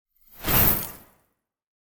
Free Frost Mage - SFX
ice_teleport_out_02.wav